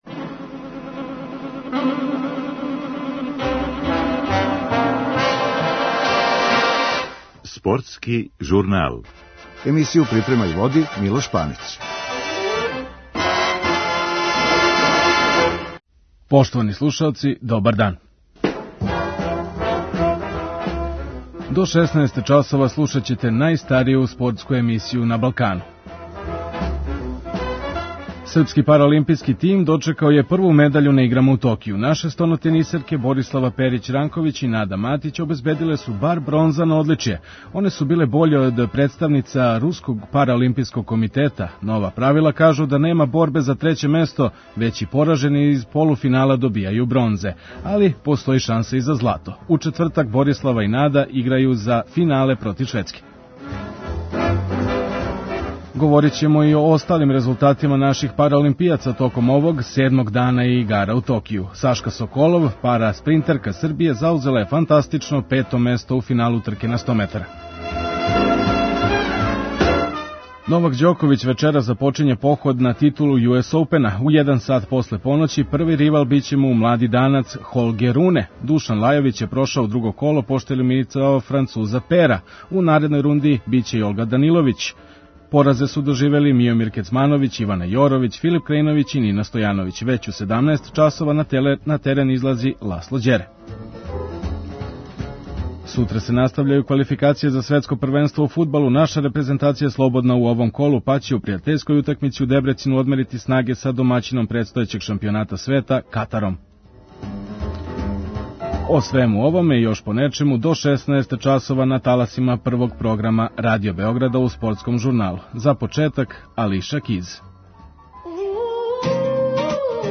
Чућете изјаве тенисера